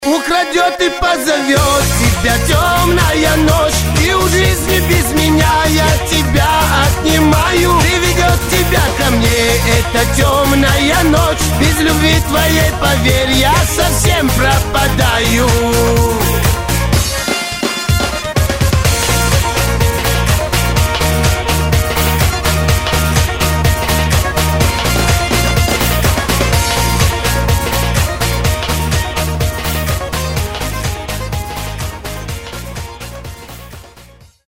веселые
кавказские